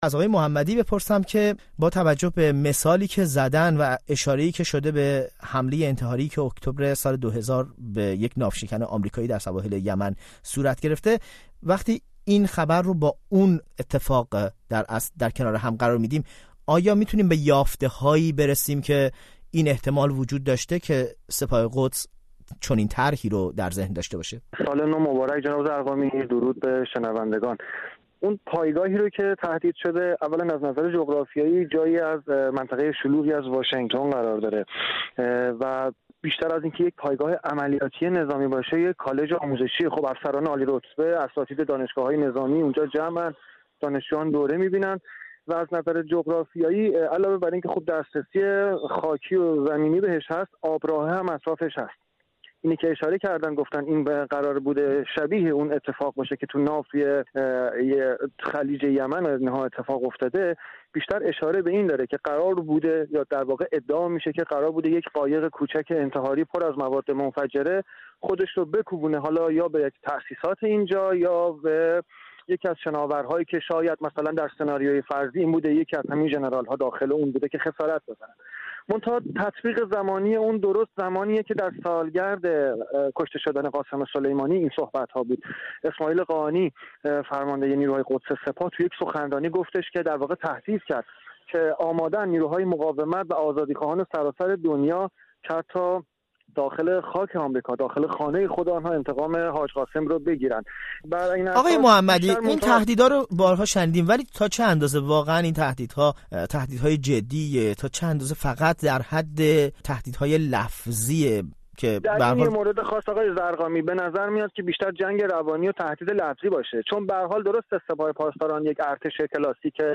میزگردی